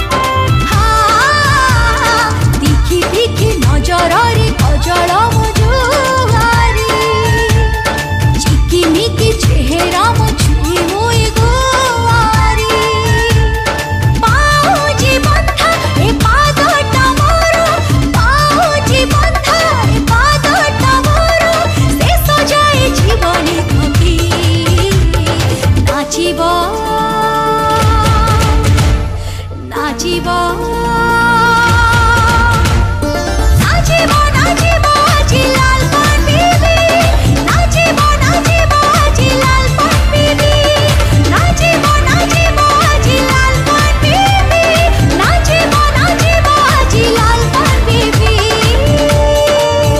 dance song